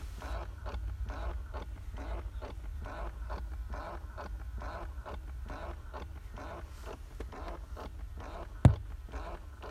manchmal höre ich ständig irgendeinen Stellmotor hinter dem Armaturenbrett (vermutlich unterm Lenkrad) arbeiten.
Wenn ich nach der Fahrt die Zündung wieder einschalte (ohne Motor), hab ich das Geräusch auch schon mal erwischt.
Hier eine Aufnahme vom Geräusch:
Irgendein Motor bewegt sich da jedenfalls immer wieder hin und her.